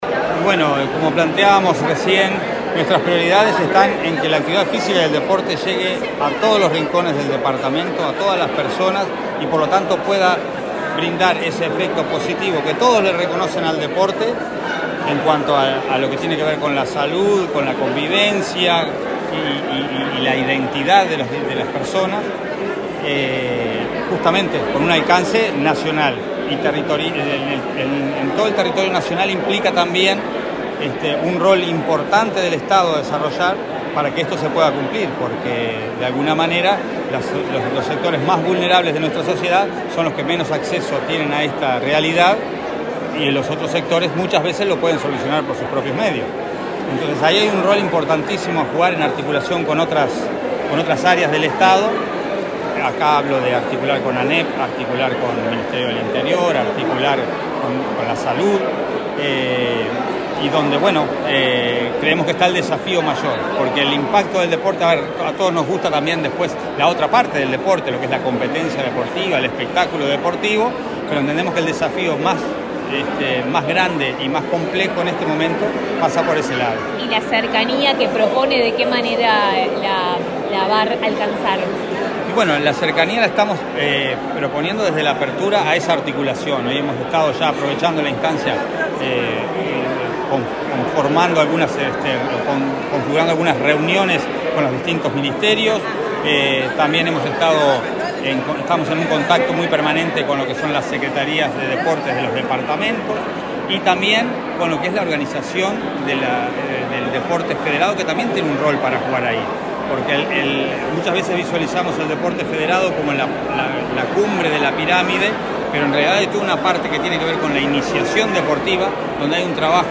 Declaraciones del director nacional del Deporte, Alejandro Pereda
El director nacional del Deporte, Alejandro Pereda, entrevistado por medios periodísticos, informó acerca de las prioridades de su gestión para este quinquenio. Pereda asumió este martes 11 en la Torre Ejecutiva la titularidad de la Secretaría Nacional del Deporte, acompañado por Gabriela Freire, en la subdirección de ese organismo.